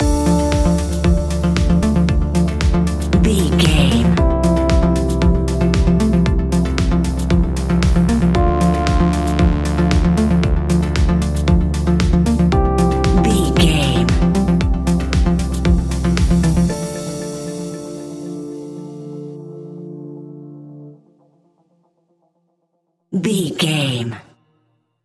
Modern Electronic Dance Pop 15 Sec.
Aeolian/Minor
groovy
uplifting
futuristic
driving
energetic
repetitive
synthesiser
drum machine
electric piano
synthwave
synth leads
synth bass